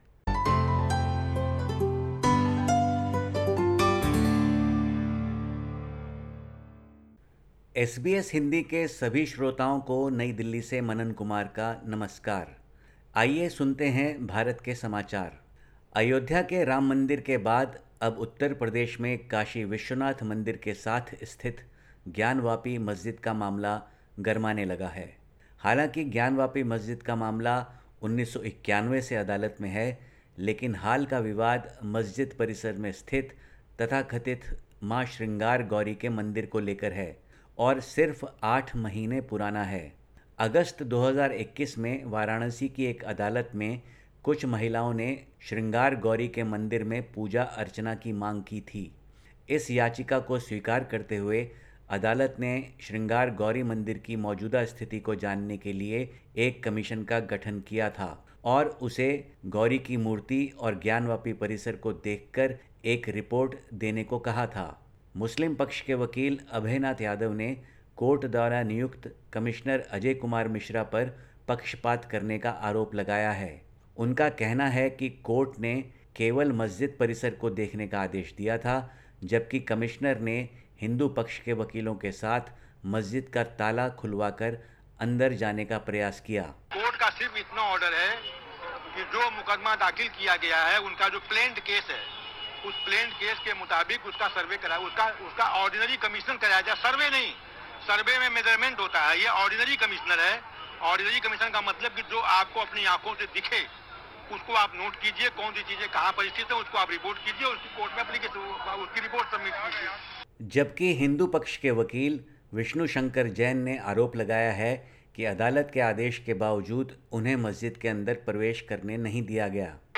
Listen to the latest SBS Hindi report from India. 09/05/2022